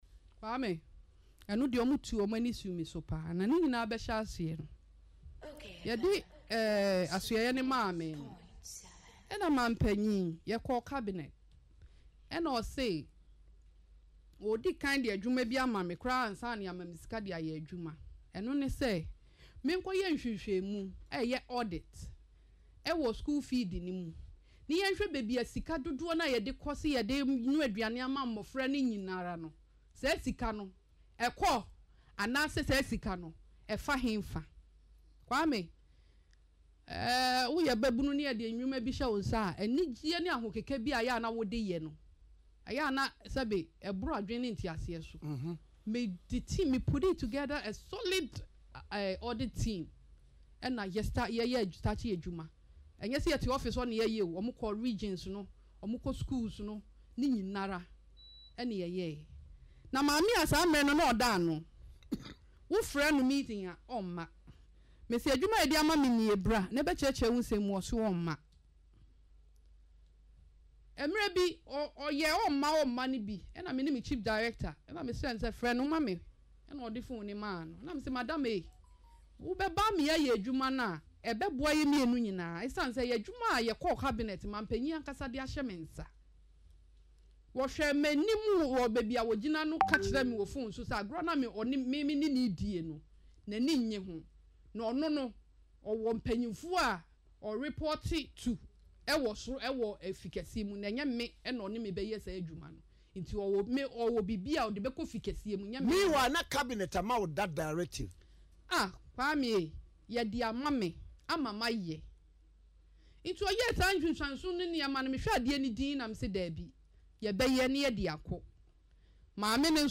Speaking in an interview on Accra-based Okay FM, the former Dome-Kwabenya MP stated that Akufo-Addo ordered the audit immediately after her appointment, even before funds were allocated to the ministry.